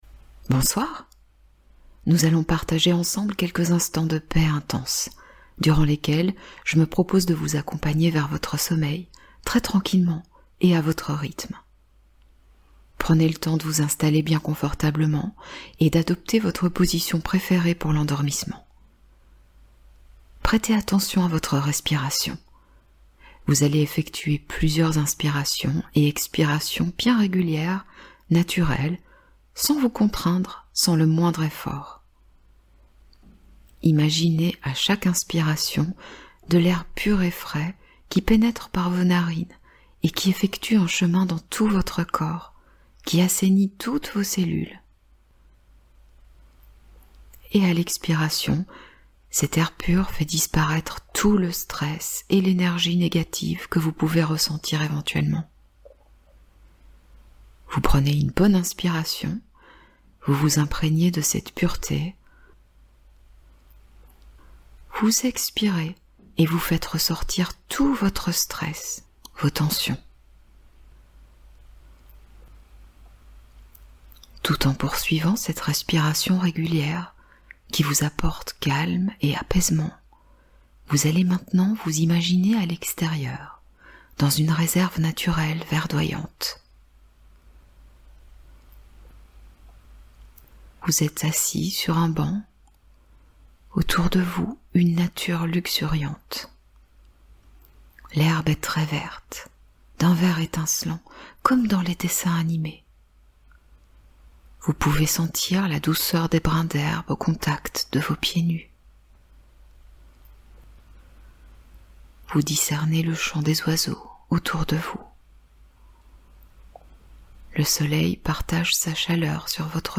Hypnose ASMR : Paix totale et reprogrammation positive nocturne